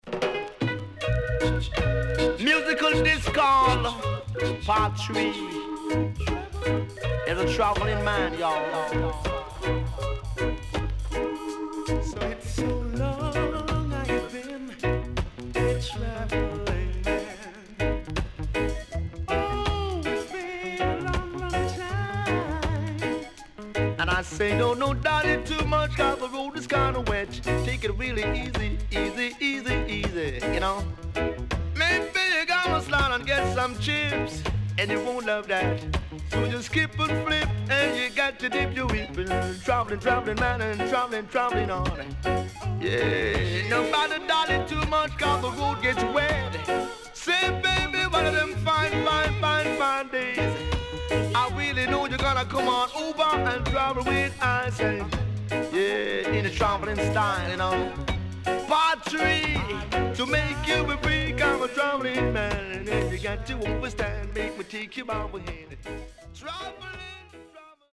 HOME > LP [VINTAGE]  >  70’s DEEJAY
SIDE B:所々チリノイズがあり、少しプチノイズ入ります。